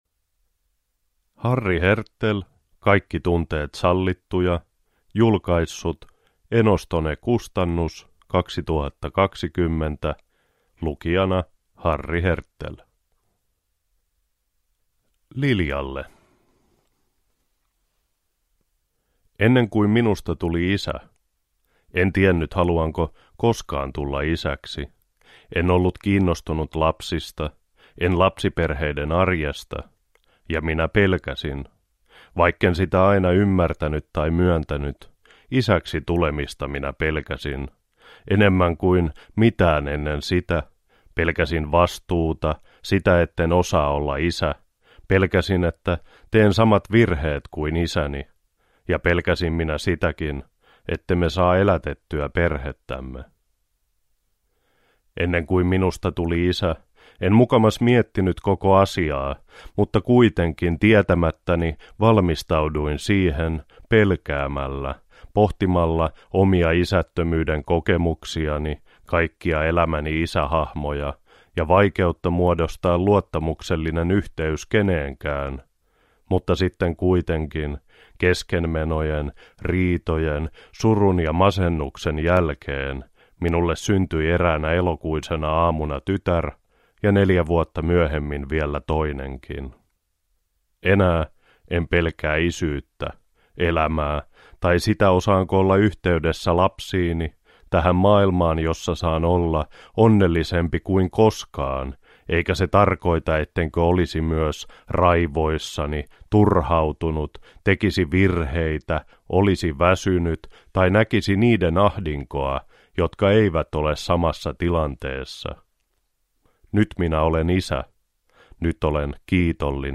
Kaikki tunteet sallittuja – Ljudbok